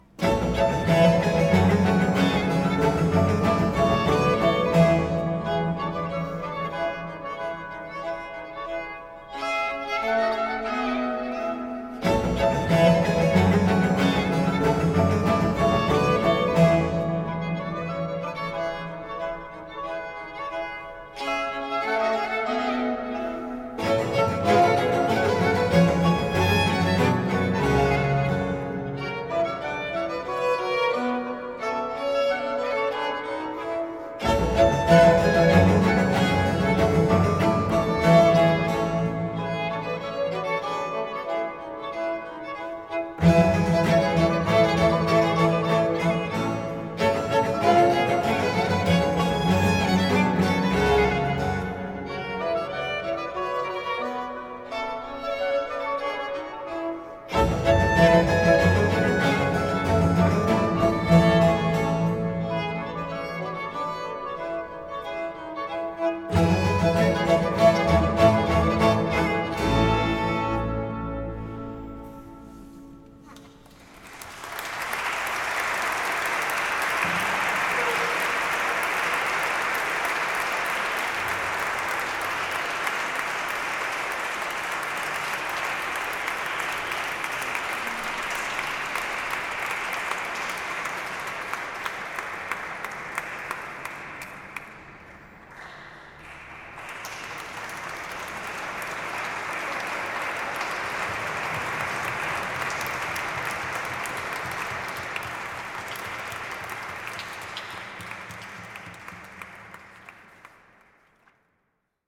First modern performance
Live: 14/08/2019 Chiesa Collegiata di Arco (TN – Italy)